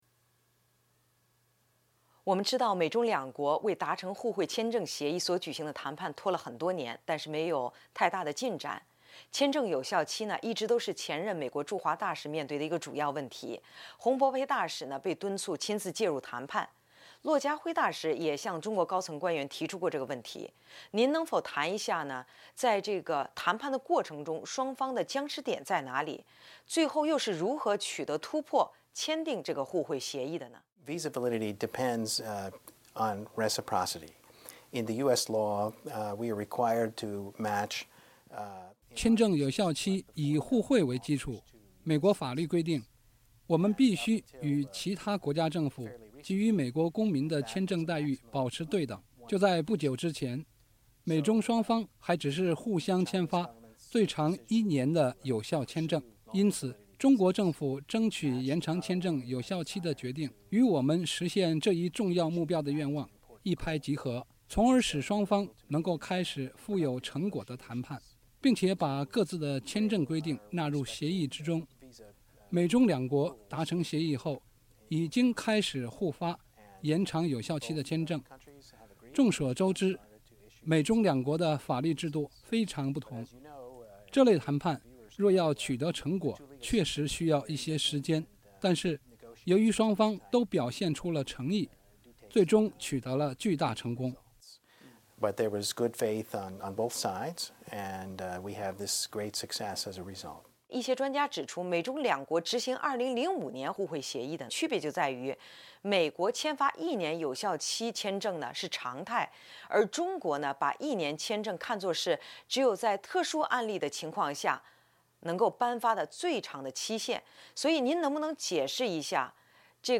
法律窗口：专访美高官谈美中互惠签证协议